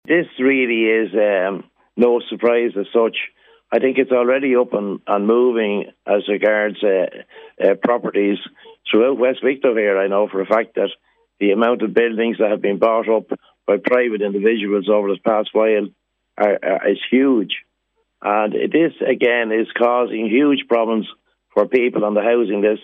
Speaking on Kildare today, she said she would not want derelict properties used just to house asylum seekers.